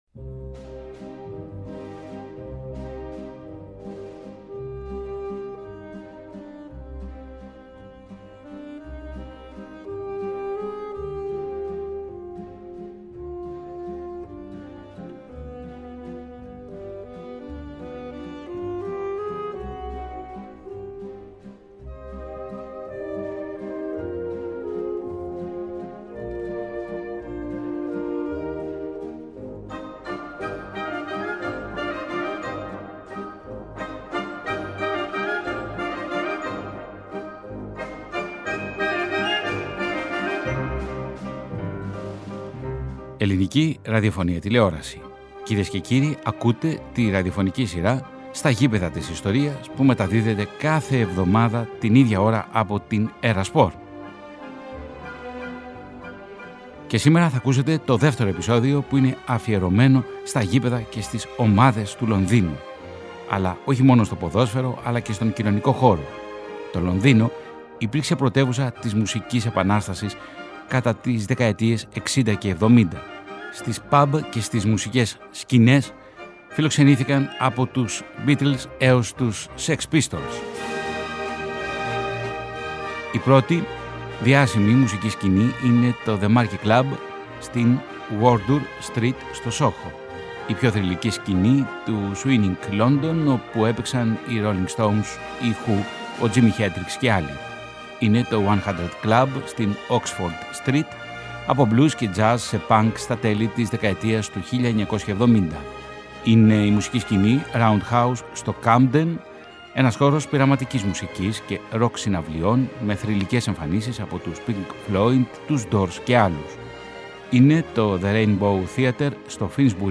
O Φεβρουάριος στην ΕΡΑ ΣΠΟΡ είναι αφιερωμένος στο βρετανικό ποδόσφαιρο και συγκεκριμένα στις ομάδες του Λονδίνου. Τρία συν ένα ραδιοφωνικά ντοκιμαντέρ, οδοιπορικά στα λονδρέζικα γήπεδα αλλά και στις παμπ και στις μουσικές σκηνές της αγγλικής πρωτεύουσας, από τις αρχές της δεκαετίας του 1960 μέχρι και τις αρχές του 1980.